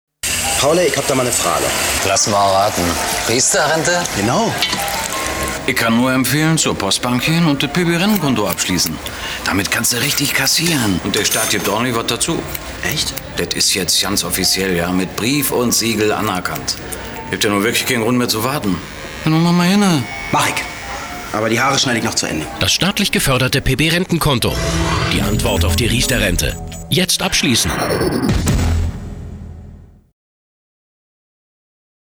werbesprecher, off-sprecher, hörspiele, hörbücher, station-voice, schauspieler, einige dialekte, tiefe stimme
norddeutsch
Sprechprobe: Sonstiges (Muttersprache):